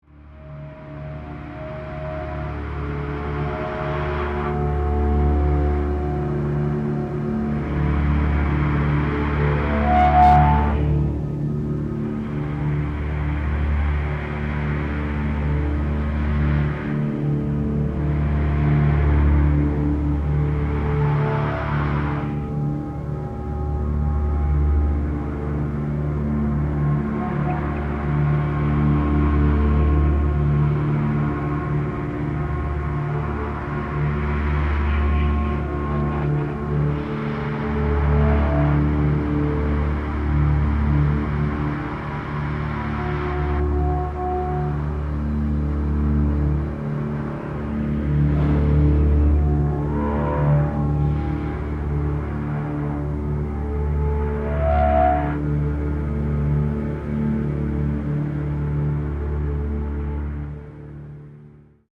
Beautiful scrape
Mind-numbingly beautiful (and heavy)